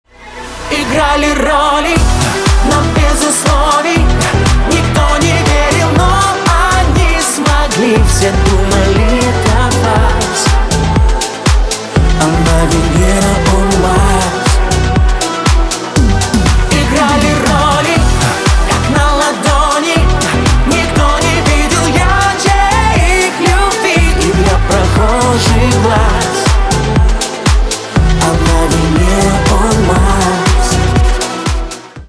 поп
чувственные